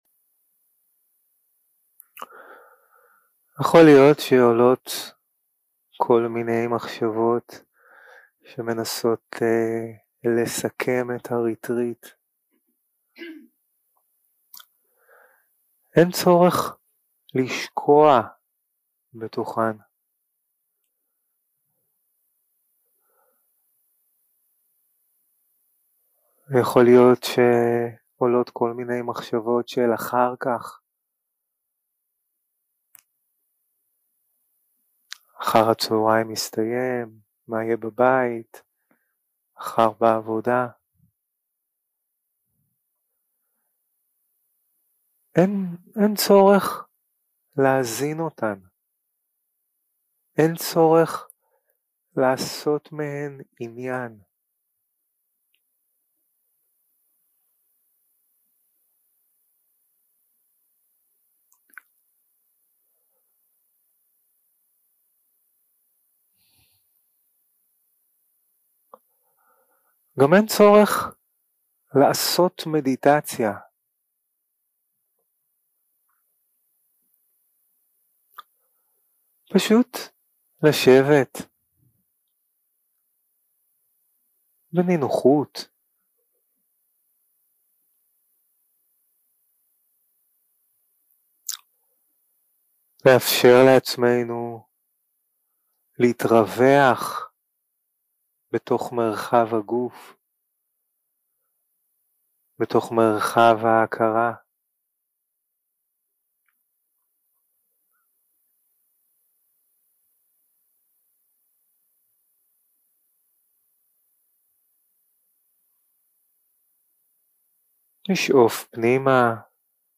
יום 4 – הקלטה 8 – בוקר – הנחיות למדיטציה - הרהורי עם מותי שלי 0:00 0:00 סוג ההקלטה: Dharma type: Guided meditation שפת ההקלטה: Dharma talk language: Hebrew